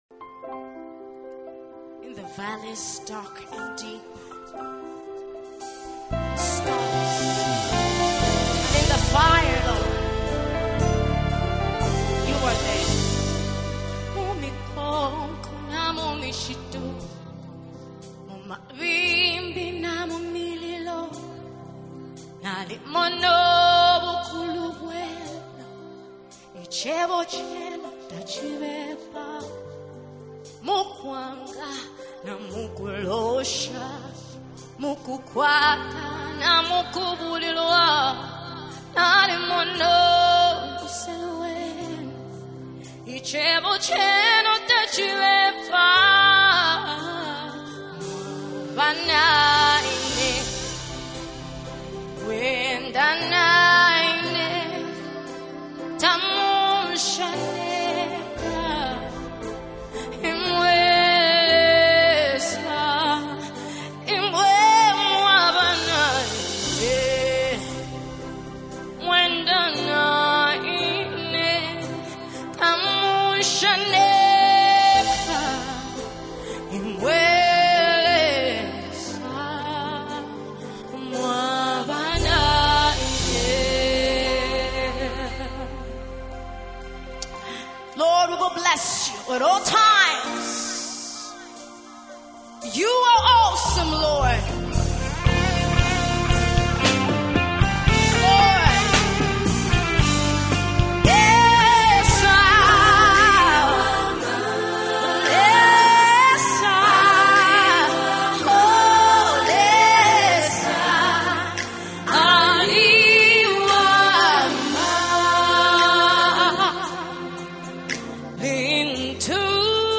🎼 GENRE: ZAMBIAN GOSPEL MUSIC